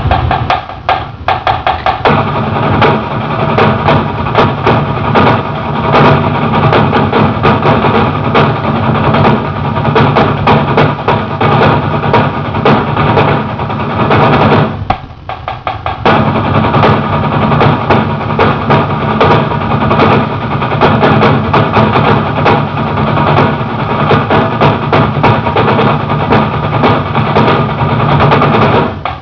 My Favorite Links The warm-ups were recorded inside so they might sound a little distorted.
Warm-Ups Higadiga Double Beat Laggotos Pain in the A** Cadence Eastside(This is the semi-new cadence)